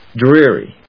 drear・y /drí(ə)ri/
• / drí(ə)ri(米国英語)